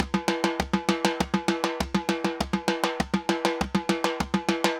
Timba_Candombe 100_2.wav